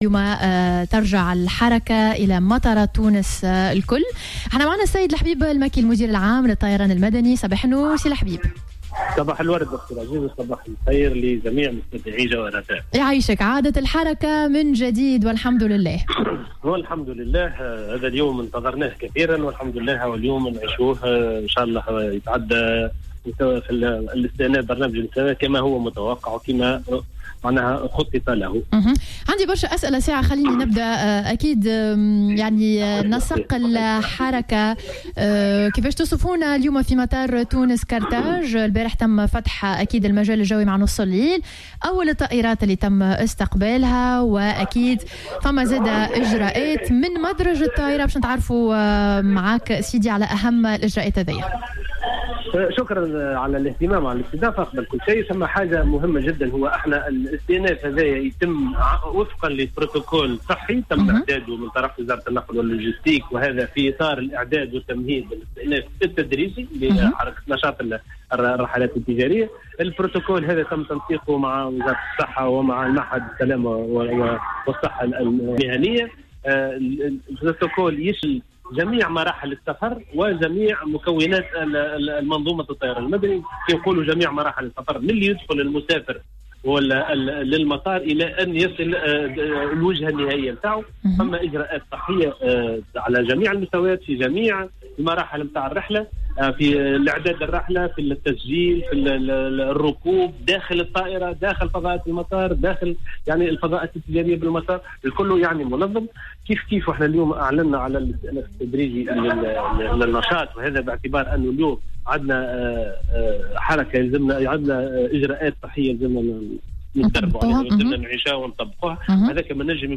وأضاف في مداخلة له على "الجوهرة أف أم" أن البروتوكول يشمل جميع مراحل السفر (منذ دخول المسافر للمطار الى غاية وصوله للوجهة النهائية).